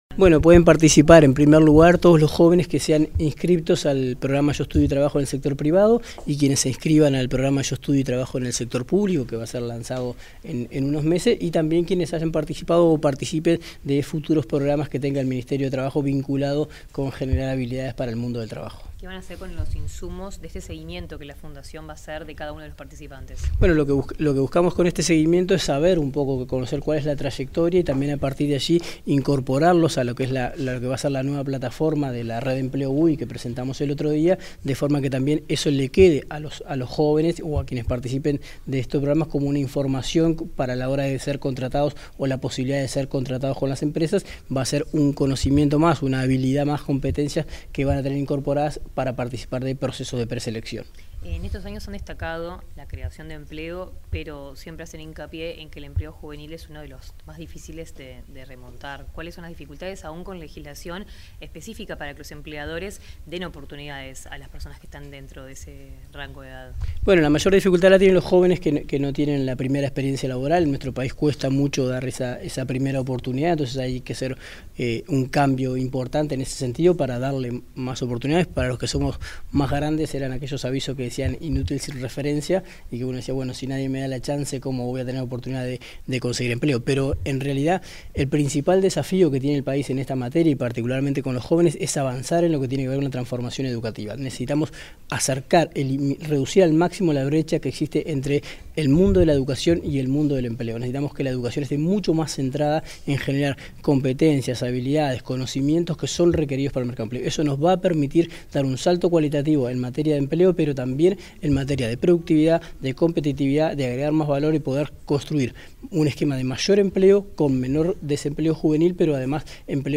Declaraciones del subsecretario de Trabajo y Seguridad Social, Daniel Pérez
Declaraciones del subsecretario de Trabajo y Seguridad Social, Daniel Pérez 30/05/2024 Compartir Facebook X Copiar enlace WhatsApp LinkedIn Tras la firma de un acuerdo para fortalecer la empleabilidad de la población joven en Uruguay, este 30 de mayo, el subsecretario de Trabajo y Seguridad Social, Daniel Pérez, realizó declaraciones a la prensa.